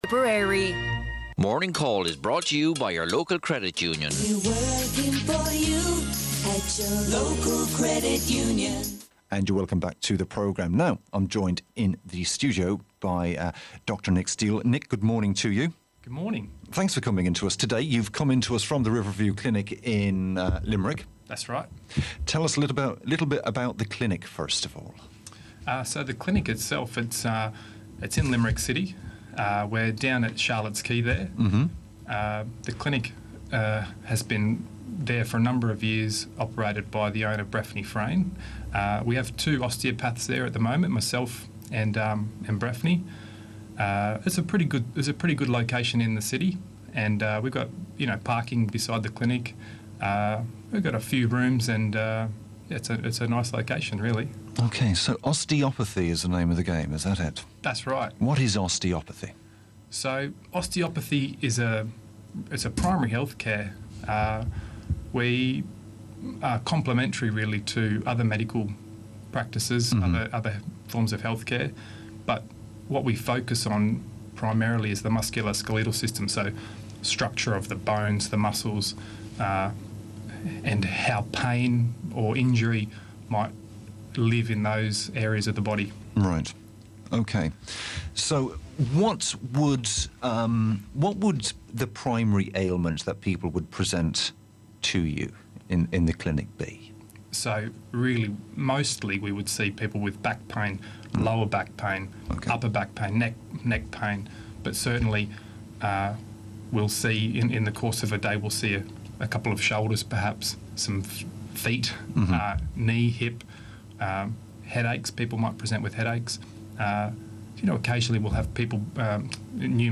Home > > Articles RIVERVIEW CLINIC ARTICLES INTERVIEW - TIPPERARY MIDWEST RADIO (DECEMBER 2014) Riverview Clinic about Osteopathy In MP3 format (14MB).
RadioInterview2 .mp3